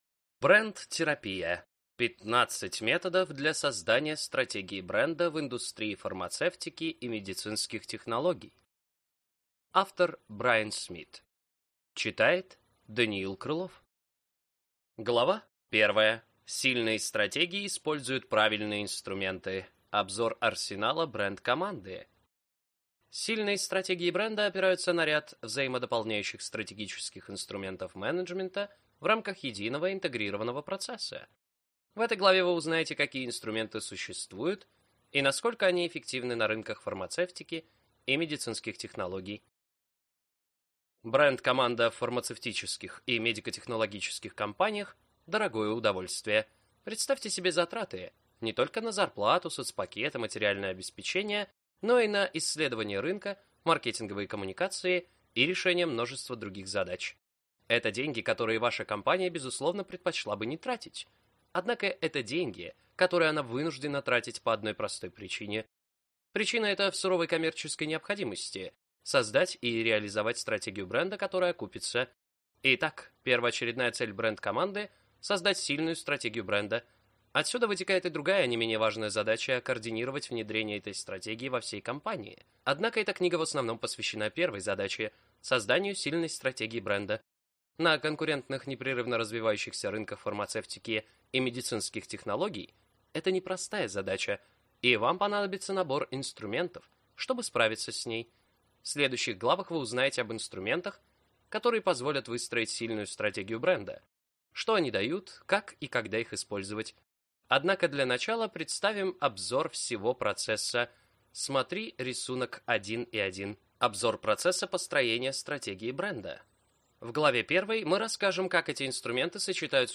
Аудиокнига Бренд-терапия. 15 методов для создания стратегии бренда в индустрии фармацевтики и медицинских технологий | Библиотека аудиокниг